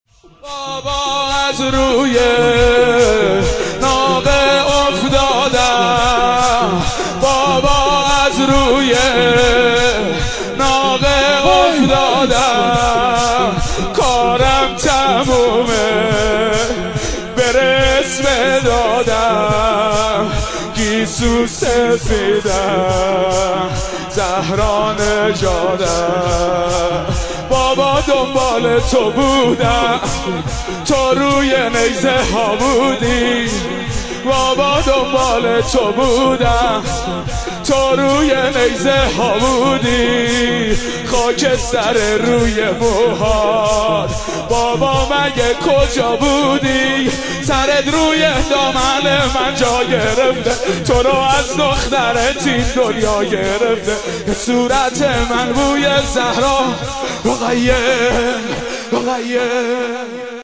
11-Shoor-3.mp3